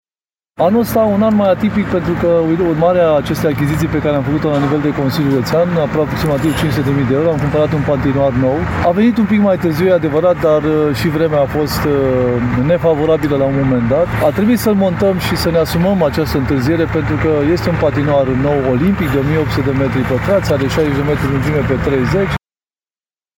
Vicepreședintele Consiliului Județean Brașov, Șerban Todorică: